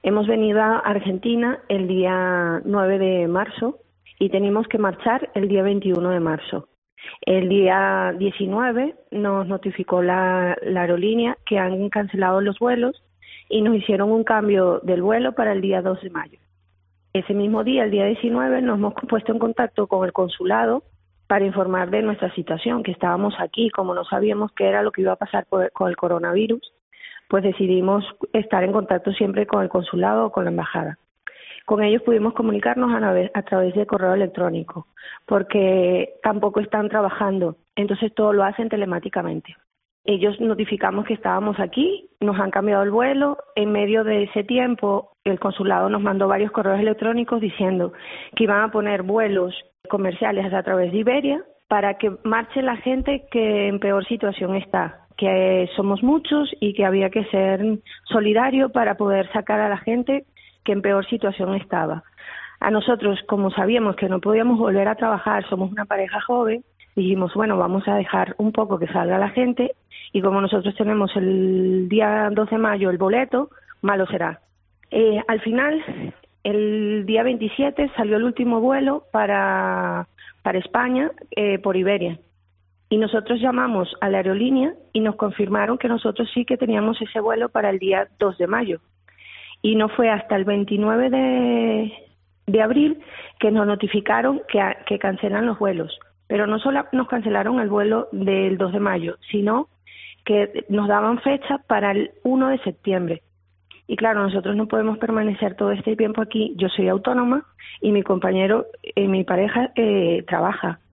Testimonio de los vecinos de Caldas confinados en Argentina